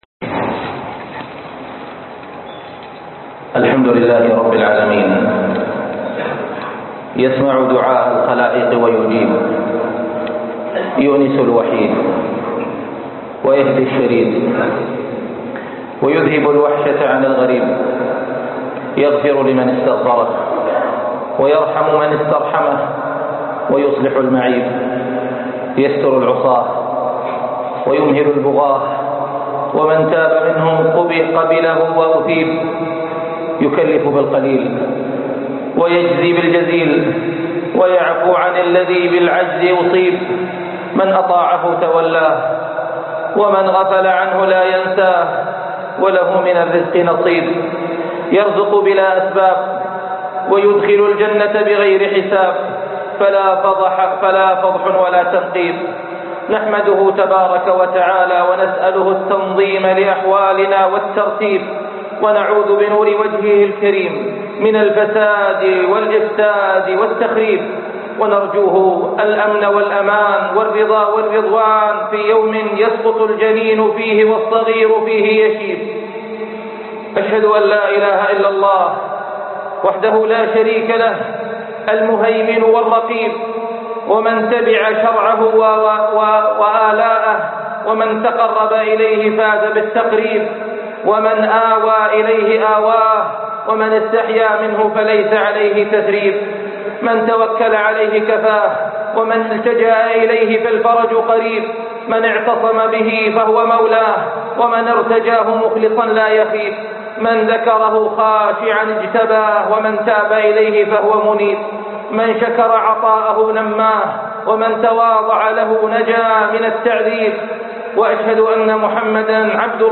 الديون - خطب الجمعة